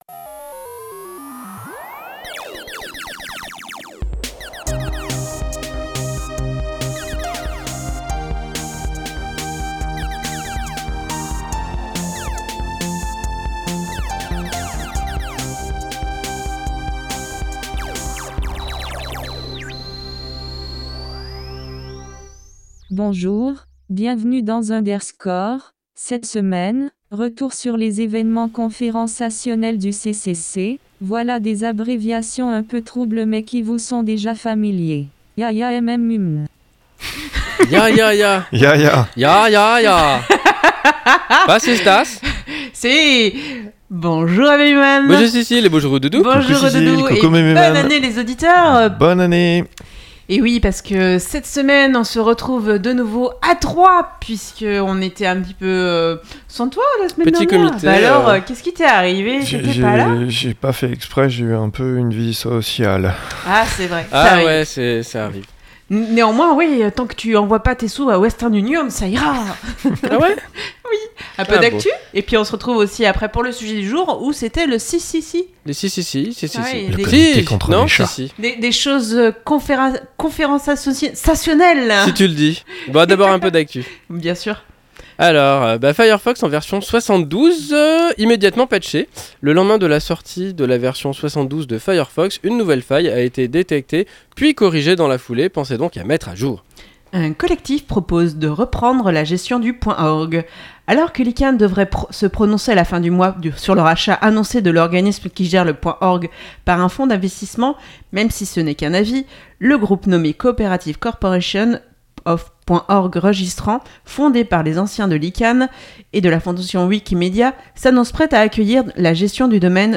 Le 36C3 De l'actu ; une pause chiptune ; un sujet : le 36C3 ; l'agenda ; et astrologeek !